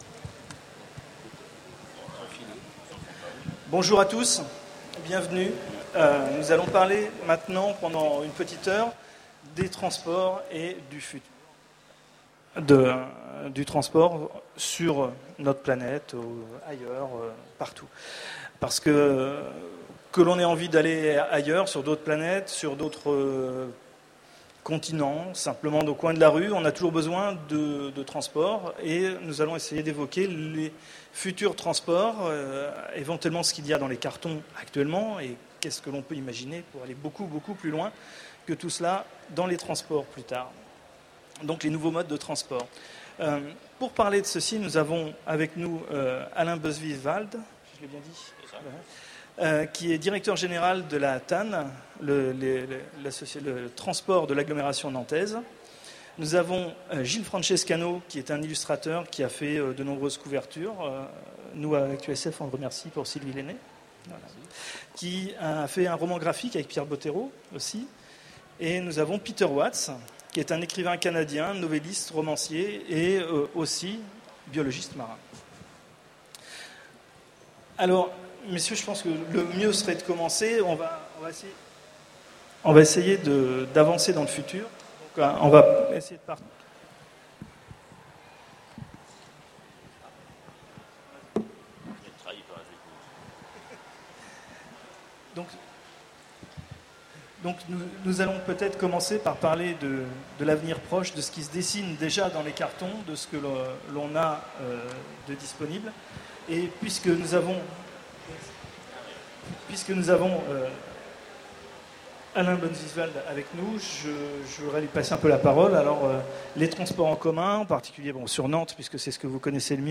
Utopiales 2010 : Conférence, Les Transports du futur
Voici l'enregistrement de la conférence " Les Transports du futur " aux Utopiales 2010. Que ce soit vers de nouveaux cieux ou à travers les villes, repousser les frontières et transformer le monde implique aussi d’inventer de nouveaux modes de transport...